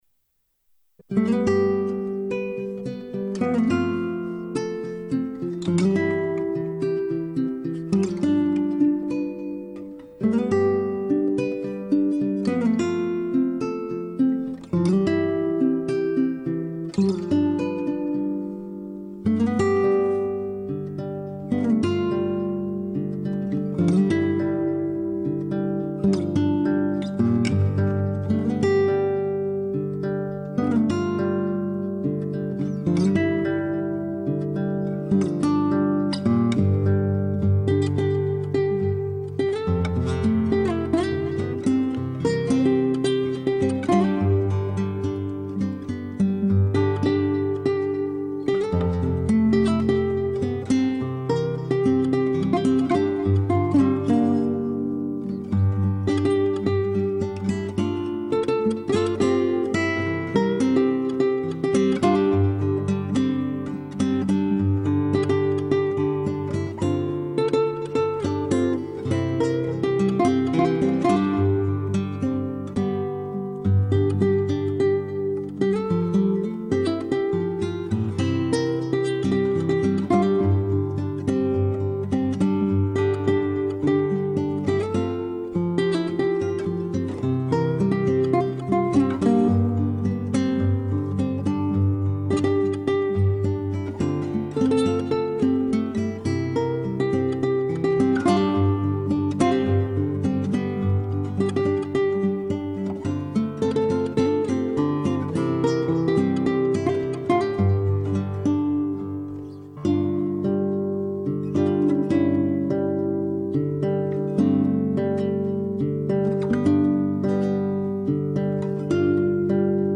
Subtle, but great.